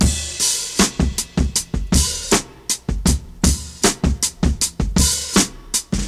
• 79 Bpm 2000s Drum Loop D Key.wav
Free drum groove - kick tuned to the D note. Loudest frequency: 3715Hz